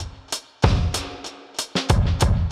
Index of /musicradar/dub-designer-samples/95bpm/Beats
DD_BeatB_95-03.wav